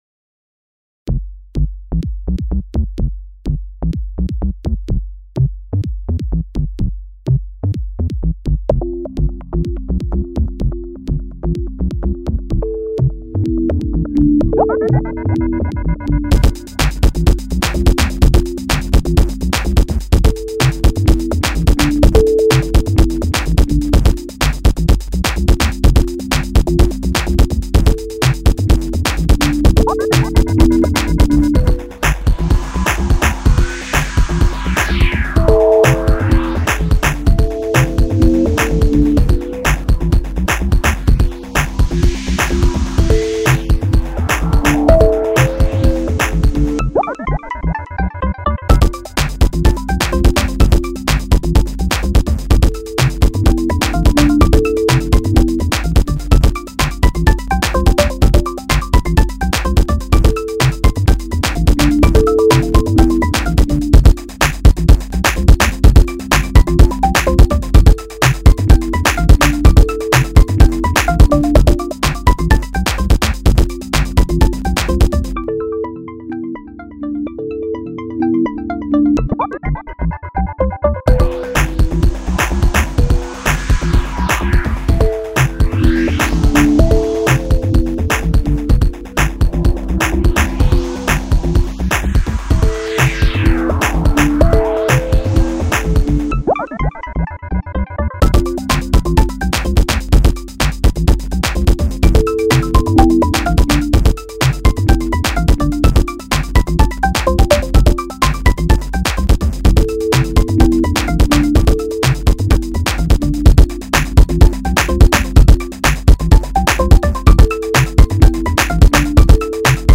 Genre Electronica